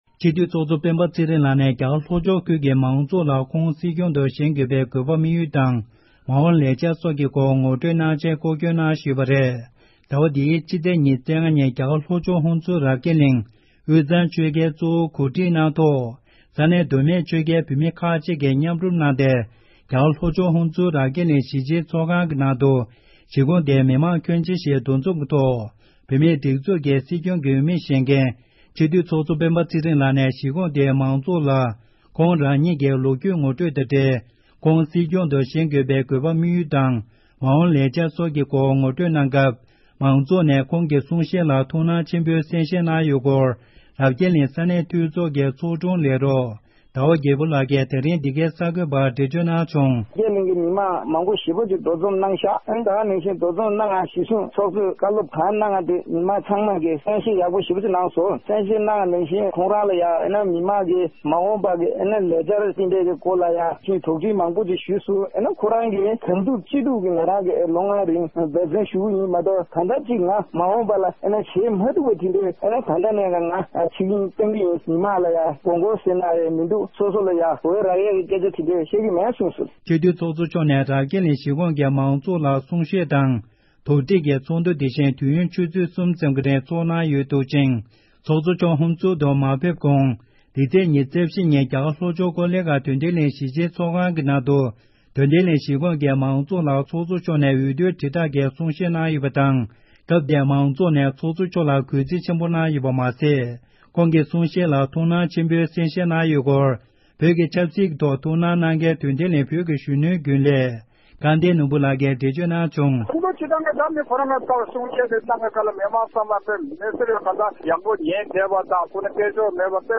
ཚོགས་གཙོ་མཆོག་ནས་ཧུན་སུར་དུ་གཏམ་བཤད། སྒྲ་ལྡན་གསར་འགྱུར།